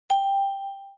beep.mp3